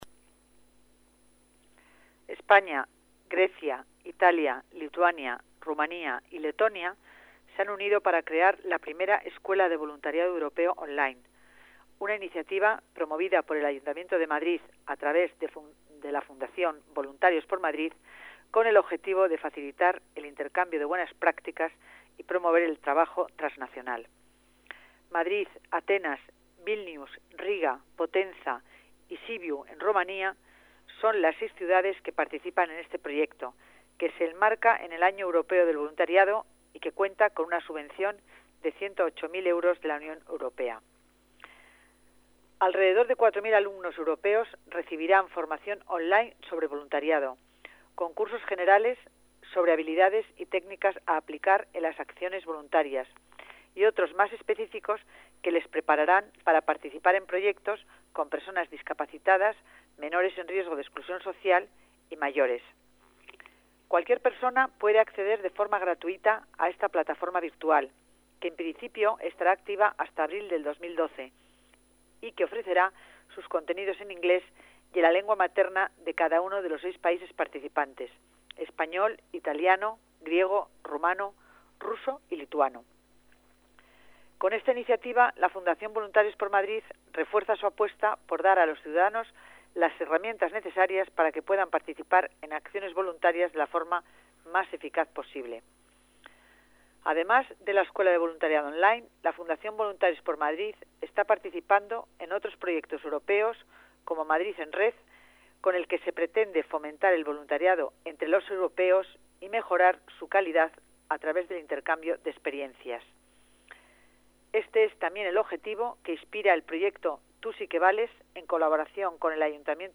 Nueva ventana:Declaraciones de Concepción Dancausa, delegada de Familia